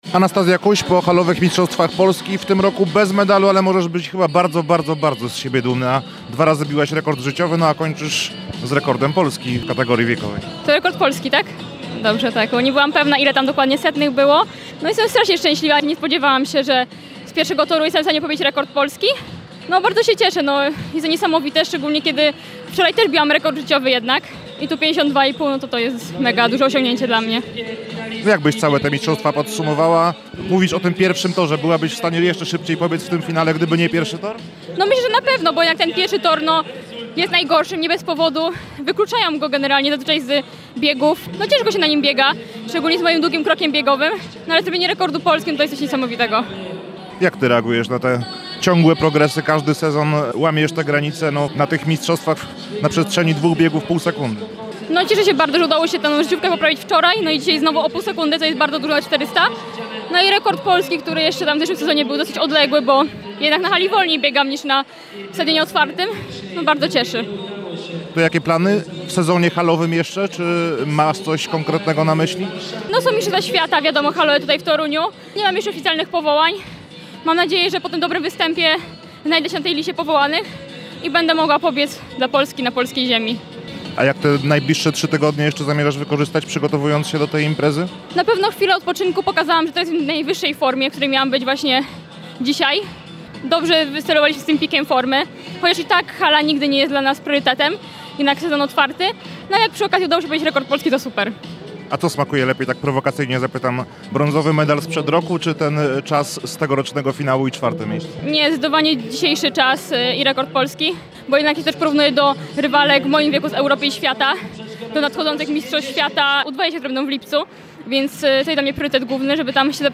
– mówiła nam tuż po niedzielnym starcie w Toruniu.